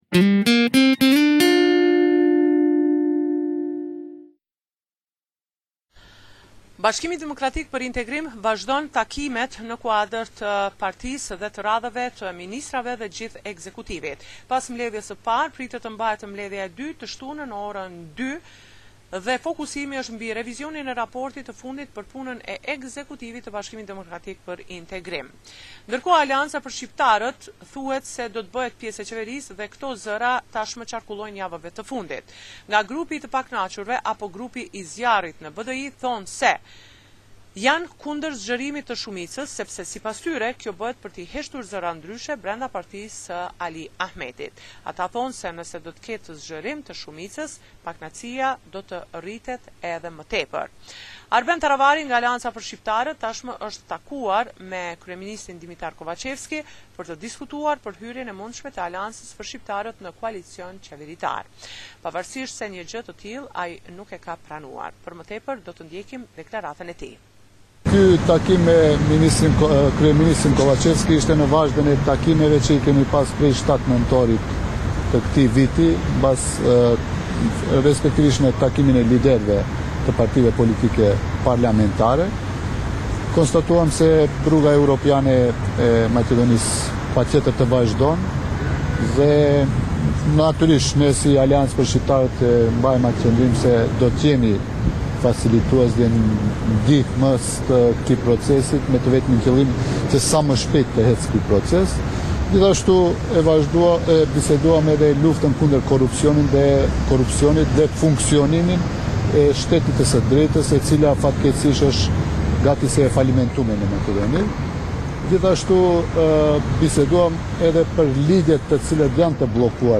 Raporti me të rejat më të fundit nga Maqedonia e Veriut.